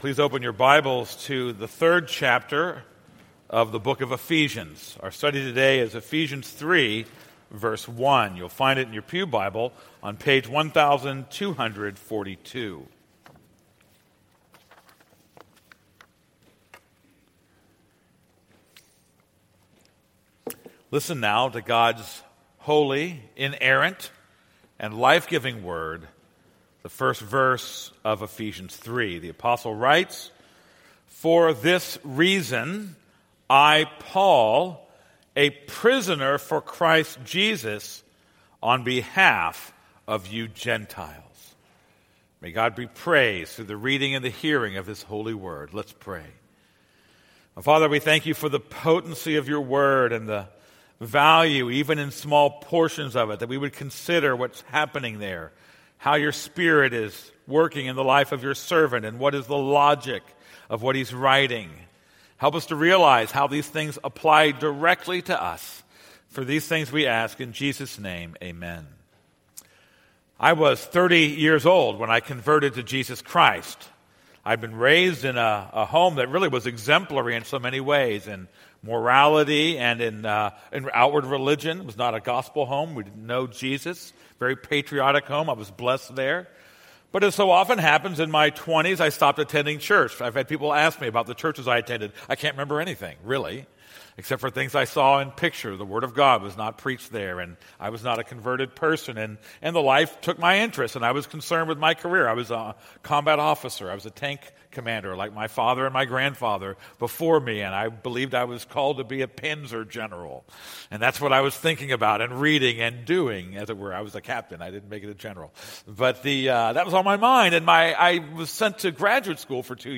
This is a sermon on Ephesians 3:1.